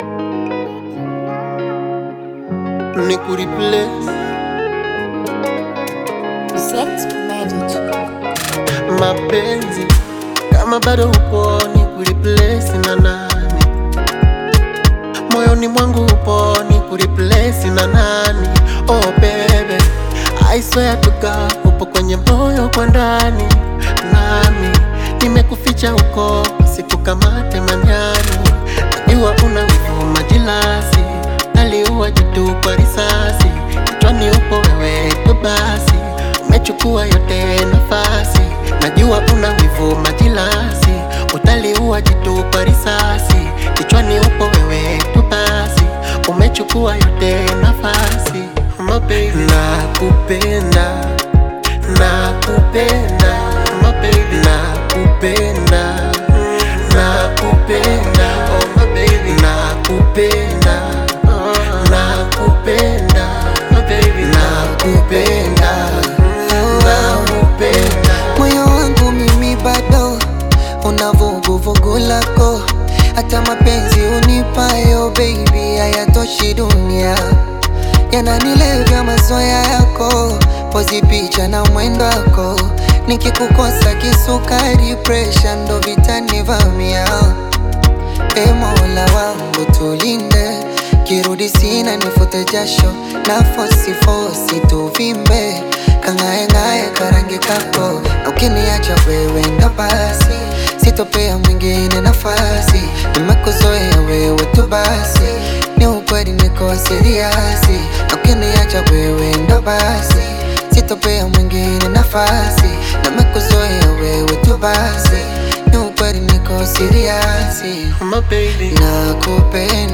Tanzanian Bongo Flava
Bongo Flava song